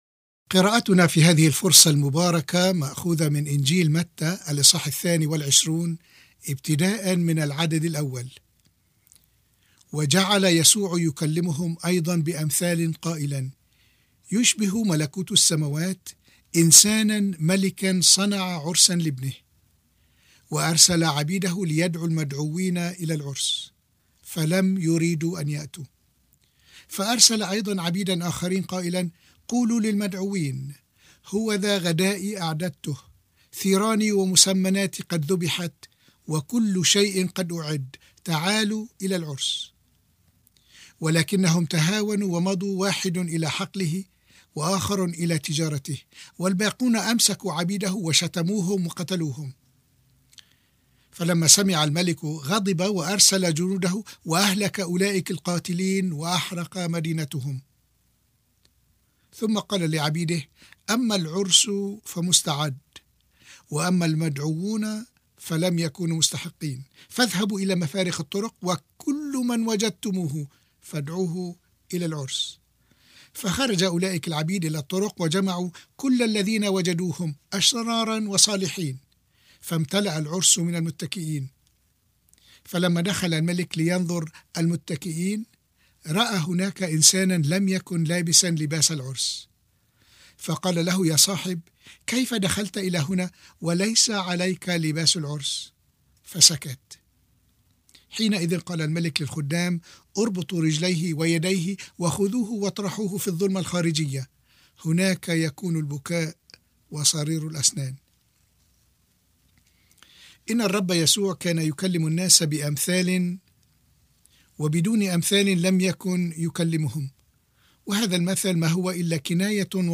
عظات